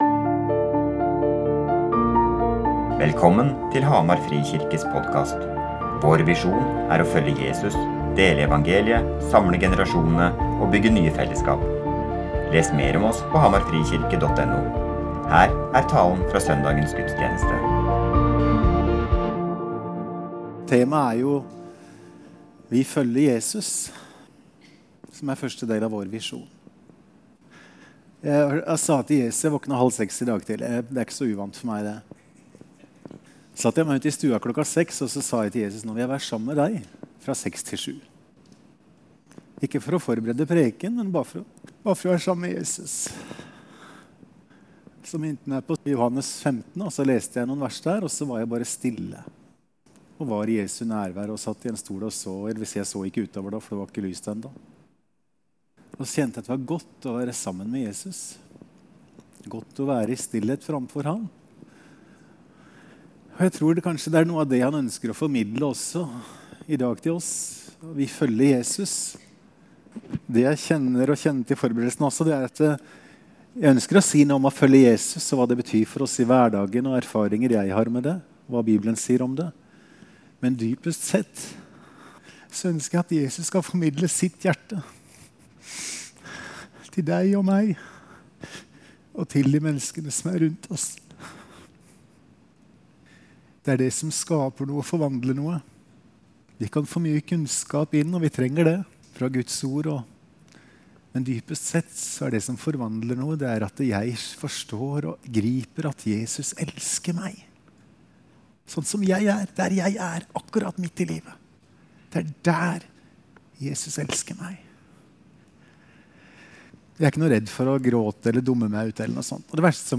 Vi følger Jesus søn 26. jan 2020 Gjestetaler Etterfølgelse Gudstjenesten https